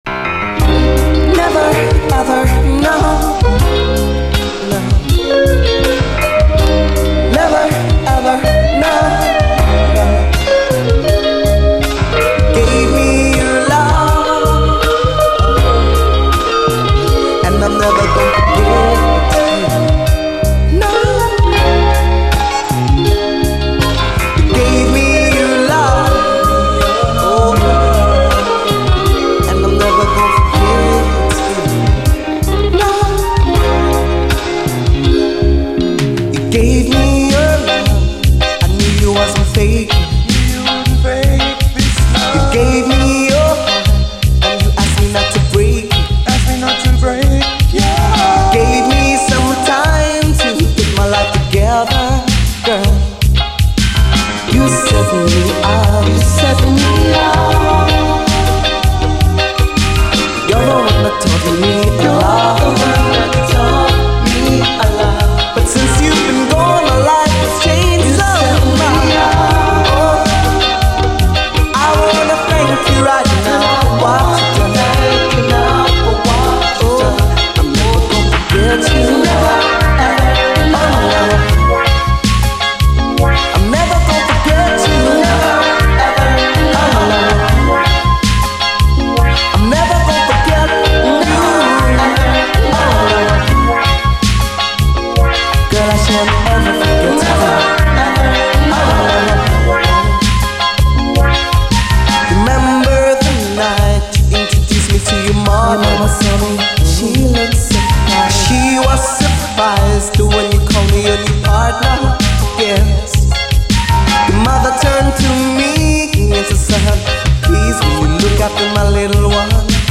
REGGAE
レアUKラヴァーズ〜アーバン・シンセ・ディスコ・レゲエ！
ゆったりメロウに始まりますがその後が素晴らしい。オリジナル・ミックスも落ち着いたメロウネスでこちらもよい。